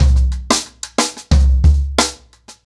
Expositioning-90BPM.1.wav